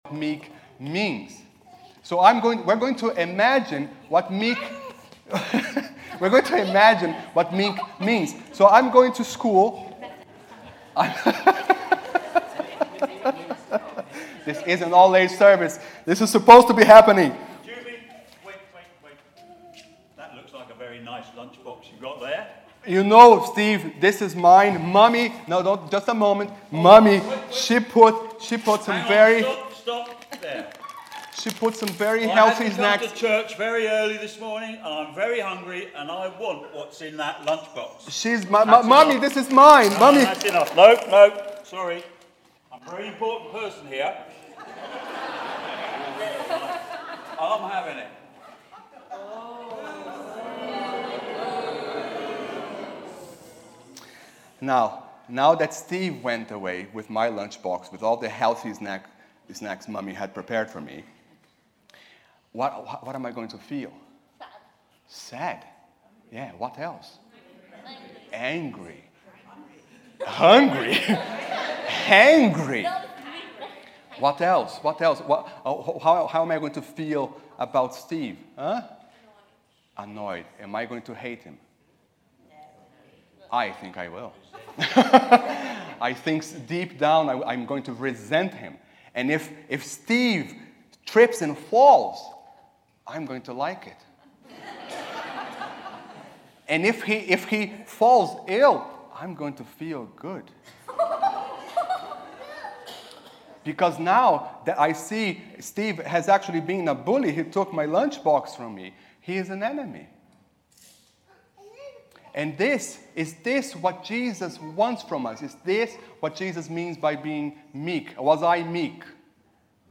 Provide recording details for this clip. Most sermons at St. Mary's are recorded and are available as computer files (.mp3) so that you can listen to them on your computer at home, or download them to transfer them to your portable music player (eg iPod).